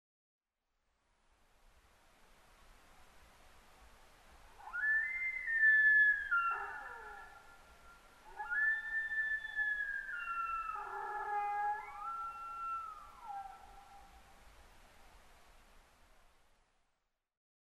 ニホンジカ　Cervus nipponシカ科
日光市稲荷川上流　alt=1330m
Mic: Panasonic WM-61A  Binaural Souce with Dummy Head
左下の方向からシカのラッティングコールが聞こえてきます。